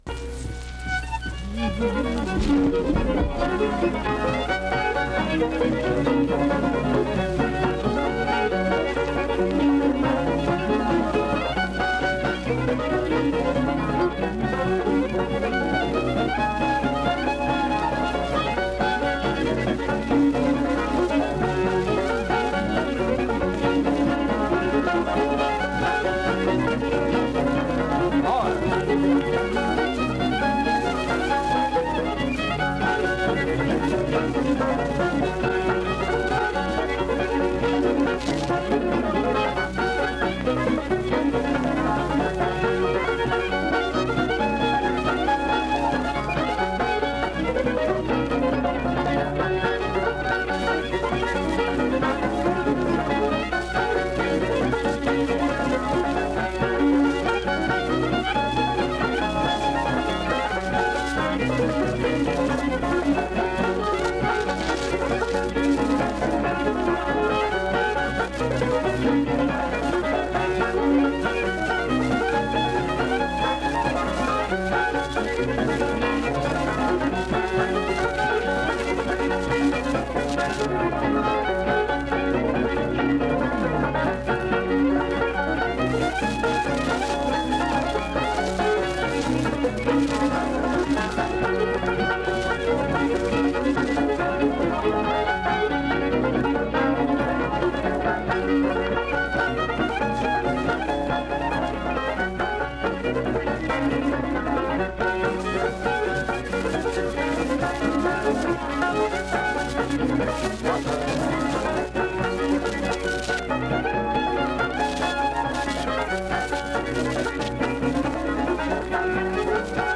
Instrumental performance with fiddle, banjo, and guitar.
Instrumental Music
Fiddle, Banjo, Guitar
Vienna (W. Va.), Wood County (W. Va.)